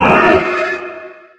58aea60d01 Divergent / mods / Soundscape Overhaul / gamedata / sounds / monsters / poltergeist / attack_hit_0.ogg 24 KiB (Stored with Git LFS) Raw History
attack_hit_0.ogg